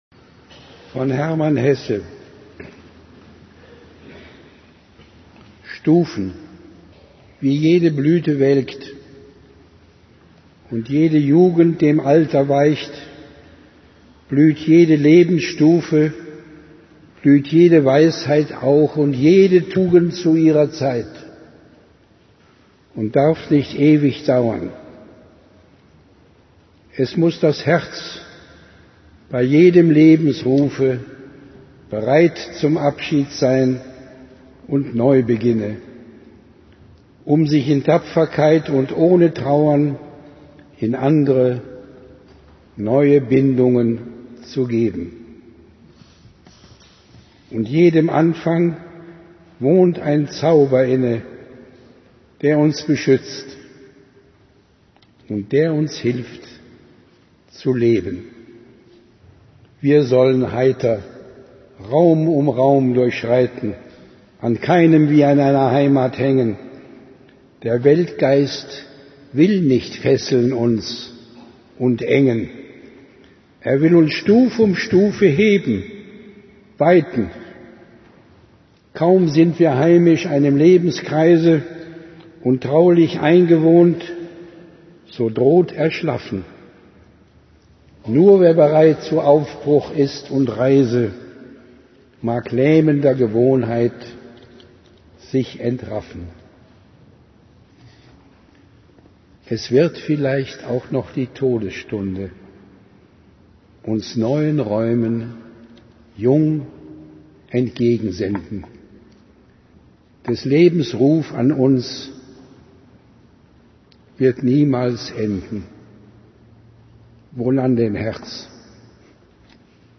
Stufen (gesprochen von H. Rühmann